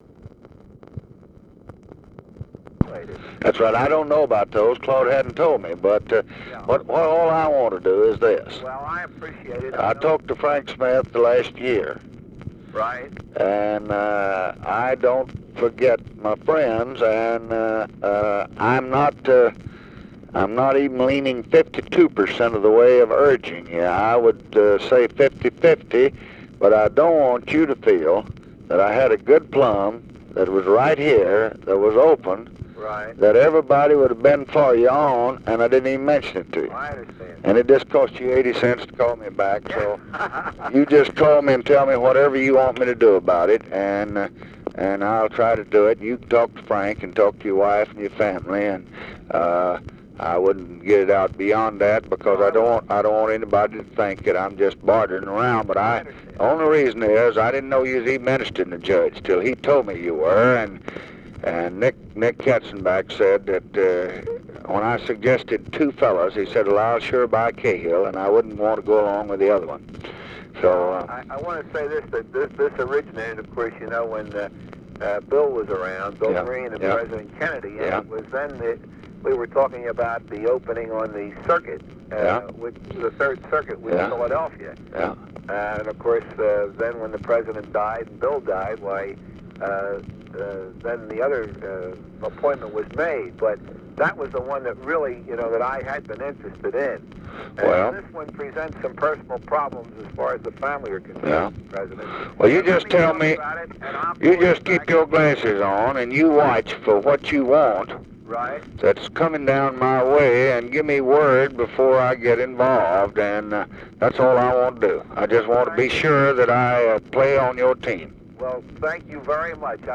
Conversation with WILLIAM CAHILL, February 20, 1965
Secret White House Tapes | Lyndon B. Johnson Presidency Conversation with WILLIAM CAHILL, February 20, 1965 Rewind 10 seconds Play/Pause Fast-forward 10 seconds 0:00 Download audio Previous Conversation with WILLIAM CAHILL?